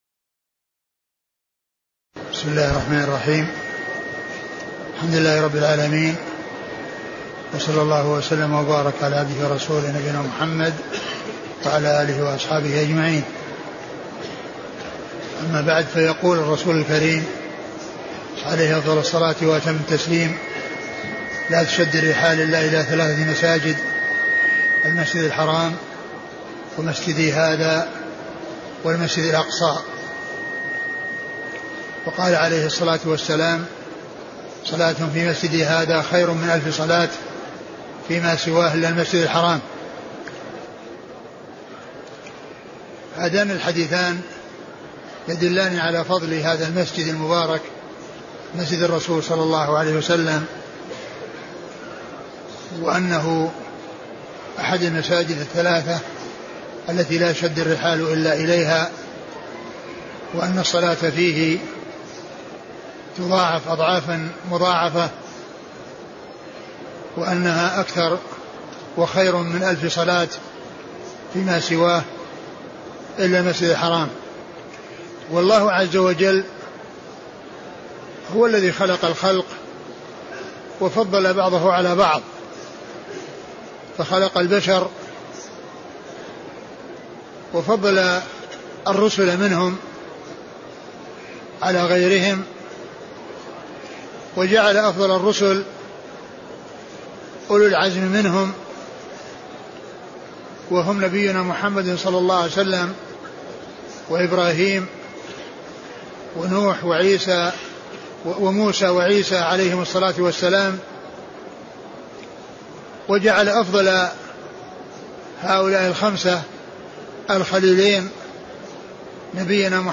محاضرة فضل الصلاة في المسجد النبوي
تاريخ النشر ١٩ ذو الحجة ١٤٢٧ المكان: المسجد النبوي الشيخ: فضيلة الشيخ عبدالمحسن بن حمد العباد البدر فضيلة الشيخ عبدالمحسن بن حمد العباد البدر فضل الصلاة في المسجد النبوي